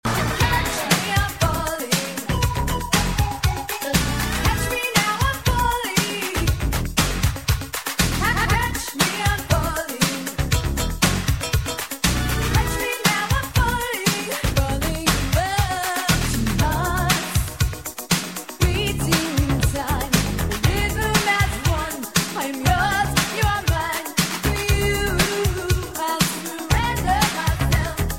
• Качество: 128 kbps, Stereo
80-ые